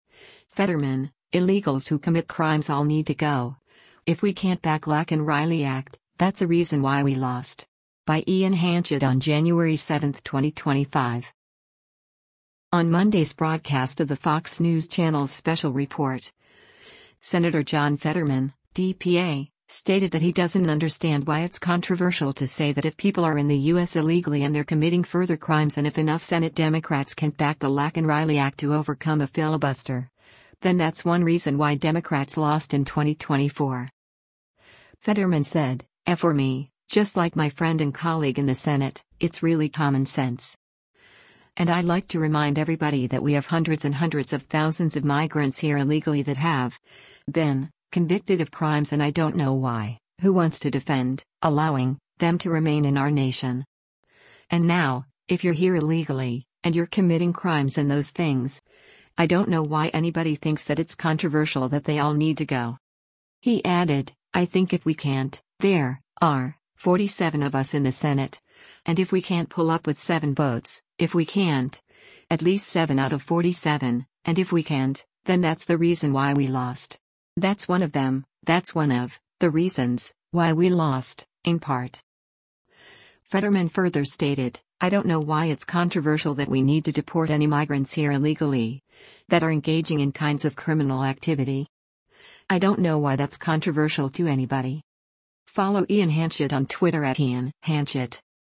On Tuesday’s broadcast of the Fox News Channel’s “Special Report,” Sen. John Fetterman (D-PA) stated that he doesn’t understand why it’s controversial to say that if people are in the U.S illegally and they’re committing further crimes and if enough Senate Democrats can’t back the Laken Riley Act to overcome a filibuster, then that’s one reason why Democrats lost in 2024.